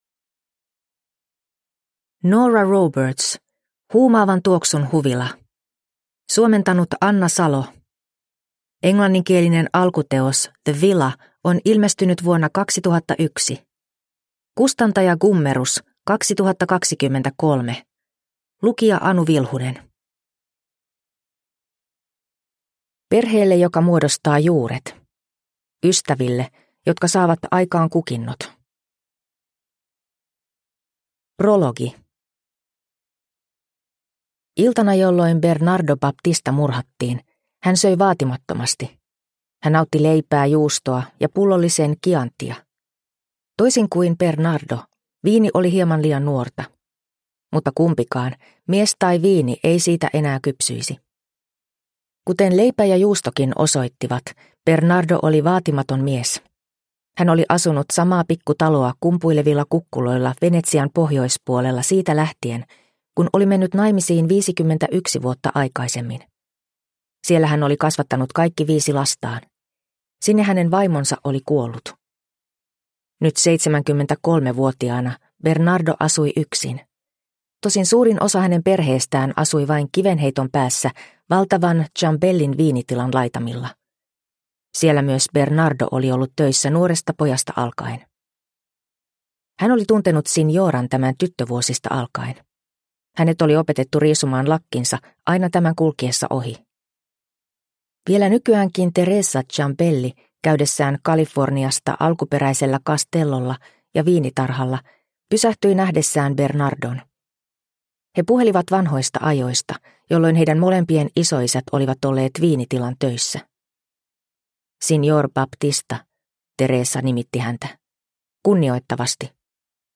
Huumaavan tuoksun huvila – Ljudbok – Laddas ner